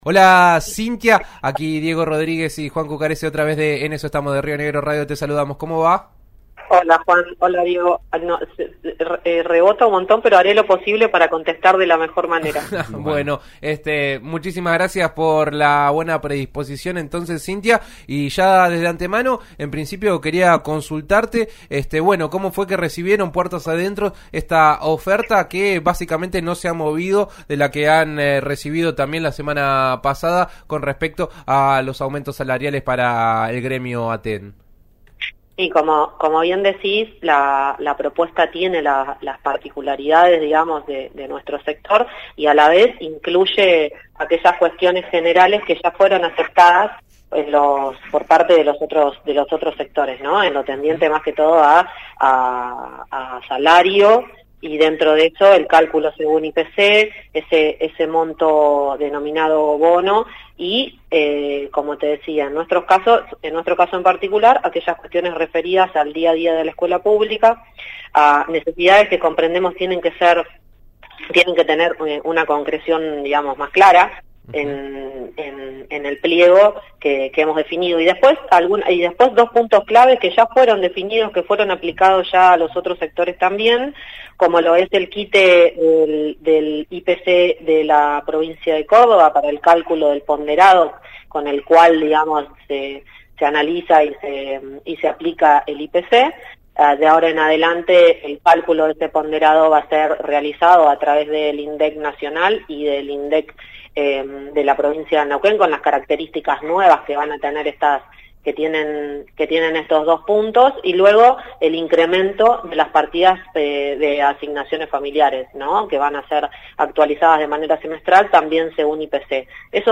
Sintonizá RÍO NEGRO RADIO.